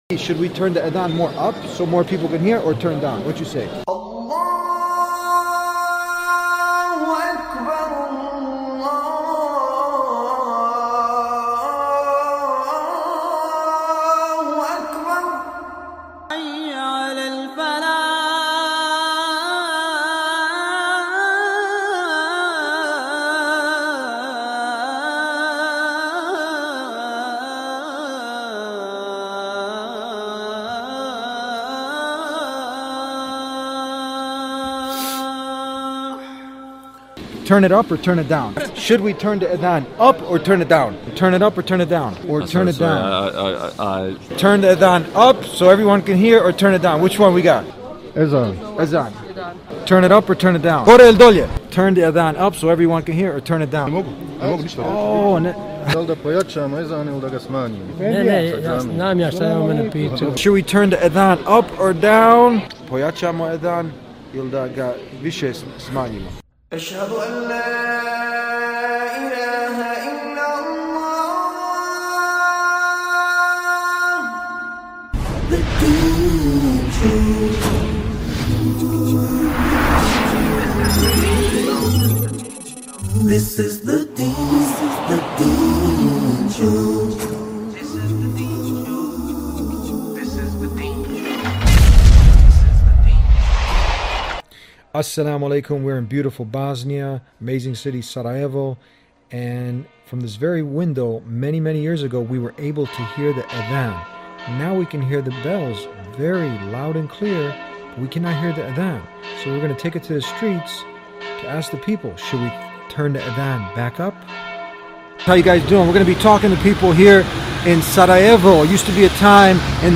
Asking people on the streets about Muslim Azan – Call to Prayer – The Deen Show